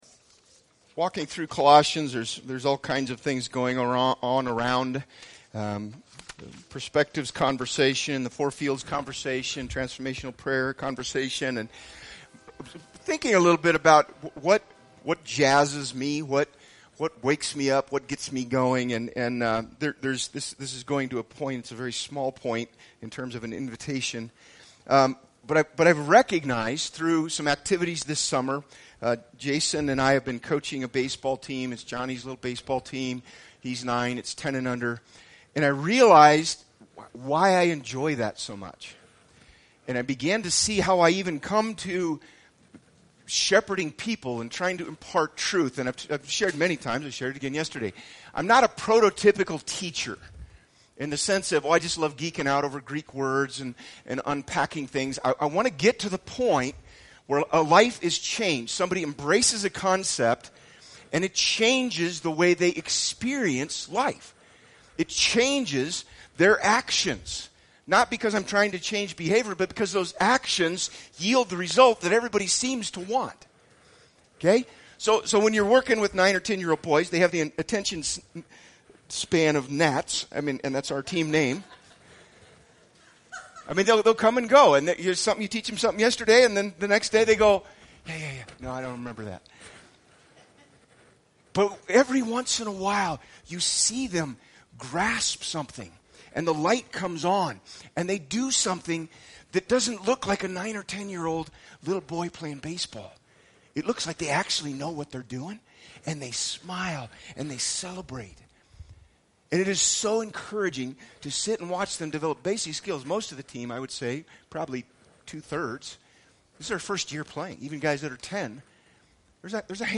BG Archives Service Type: Sunday Speaker